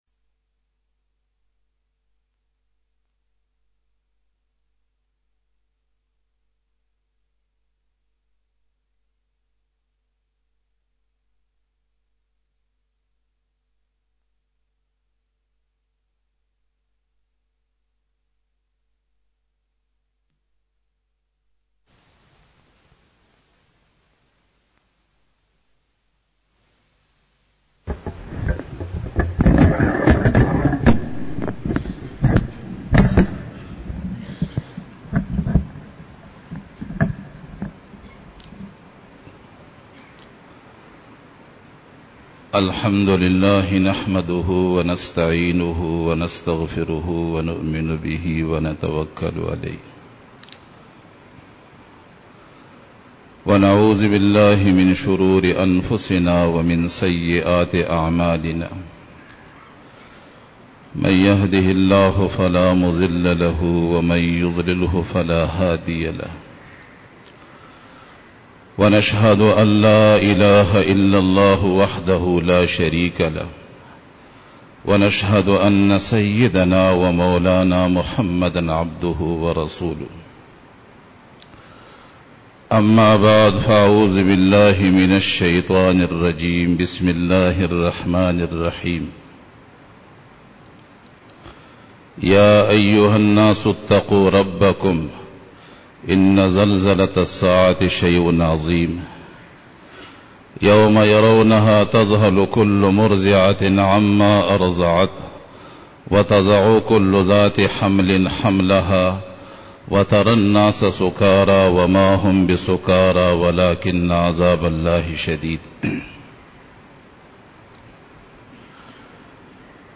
13_BAYAN E JUMA TUL MUBARAK (27-MARCH-2015) (28 JamadiUlAwwal 1436h)